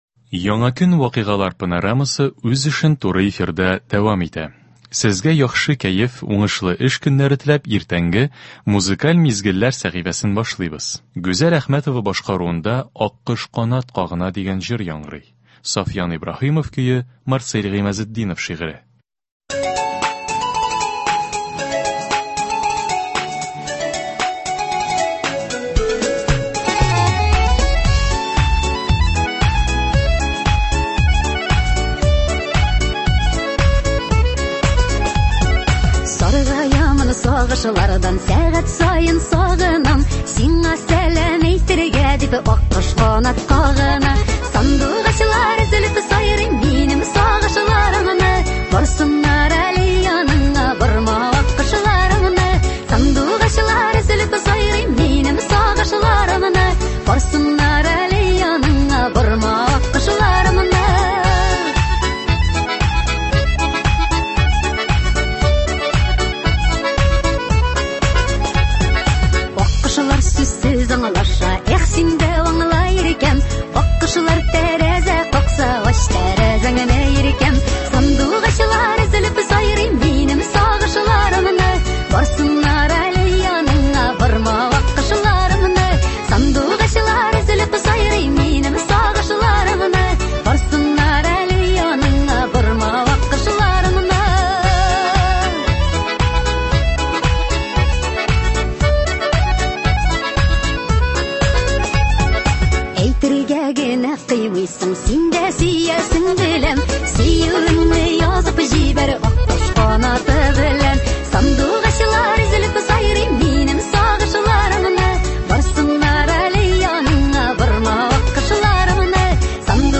Музыкаль мизгелләр – бу иртәдә безнең радио сезгә көн буена яхшы кәеф бирә торган җырлар тәкъдим итә.